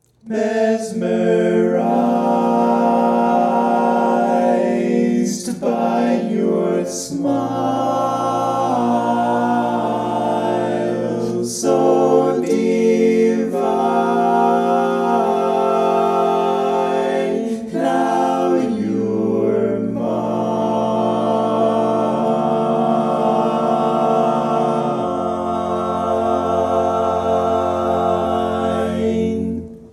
Key written in: F Major
Type: Barbershop
Learning tracks sung by